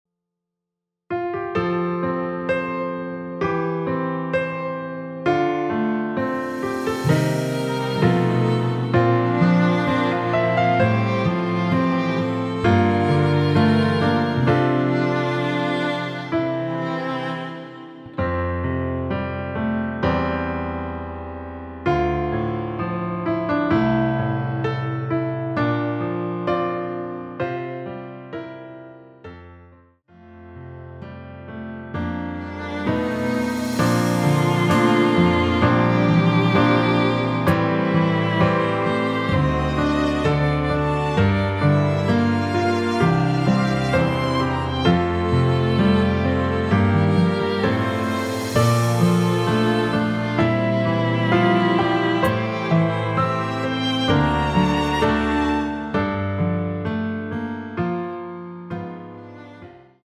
음정은 반음정씩 변하게 되며 노래방도 마찬가지로 반음정씩 변하게 됩니다.
앞부분30초, 뒷부분30초씩 편집해서 올려 드리고 있습니다.